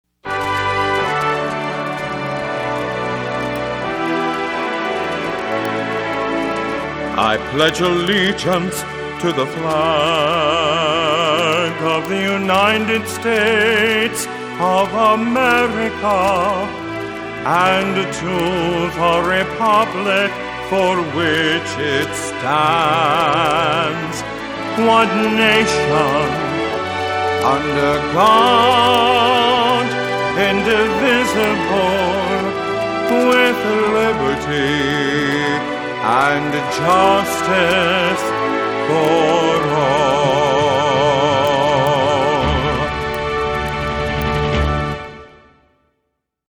Vocal M/F. Patriotic.